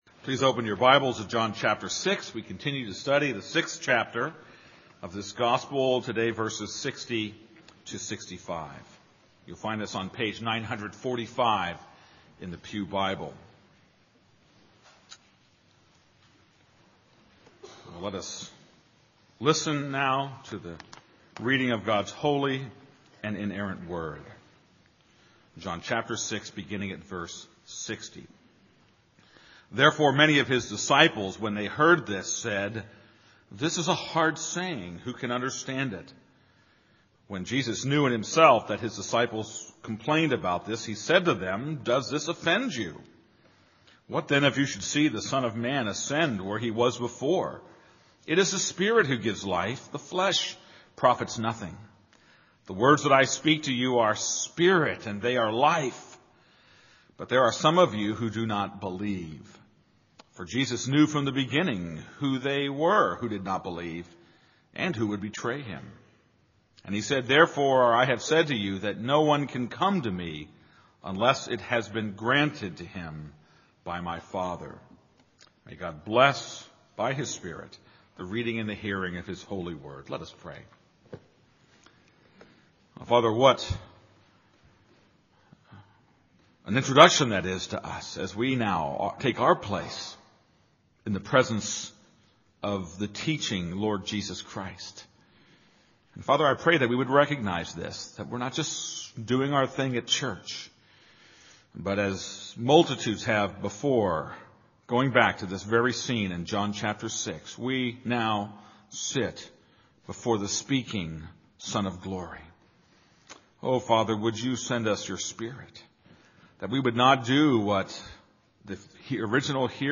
This is a sermon on John 6:60-65.